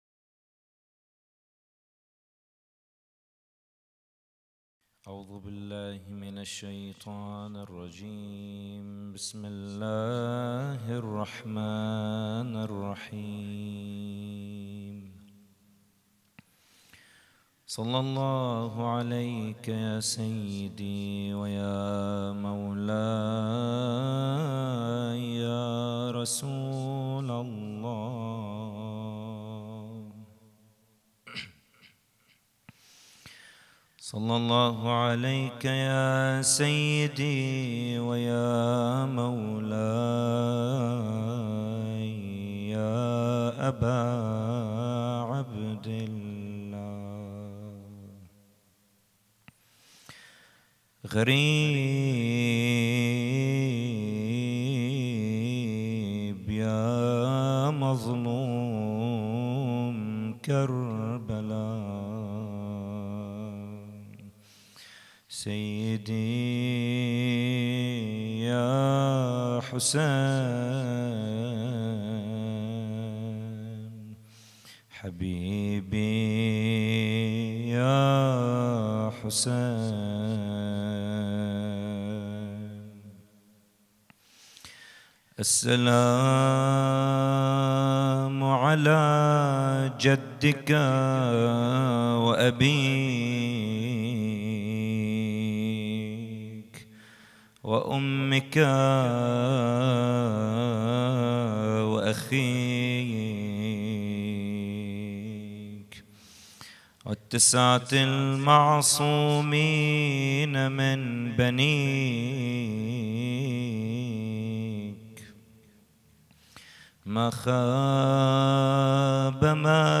محاضرة
إحياء ليلة الحادي عشر من محرم 1442 ه.ق